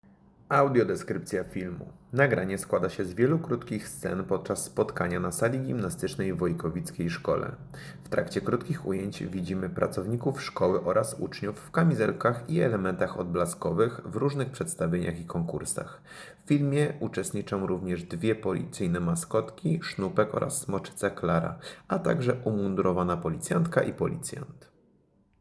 Nagranie audio Audiodeskrypcja_filmu.mp3